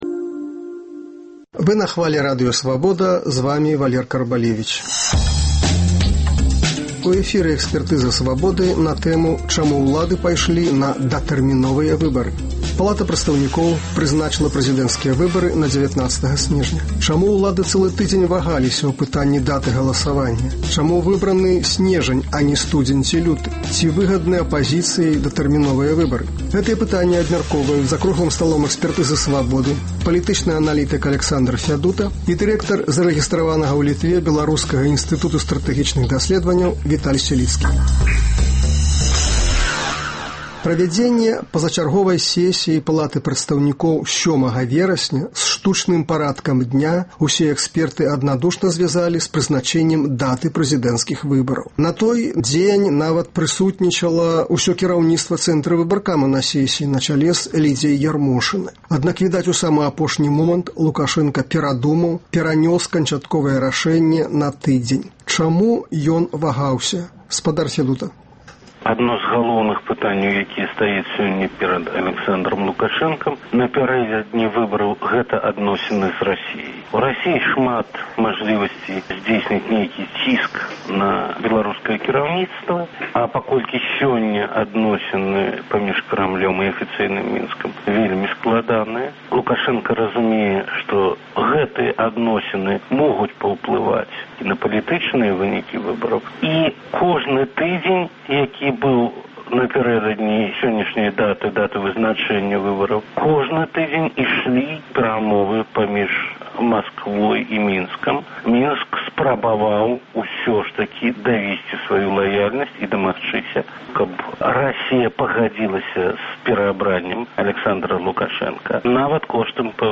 Чаму ўлады цэлы тыдзень вагаліся ў пытаньні даты галасаваньня? Чаму выбраны сьнежань, а не студзень ці люты? Гэтыя пытаньні абмяркоўваюць за круглым сталом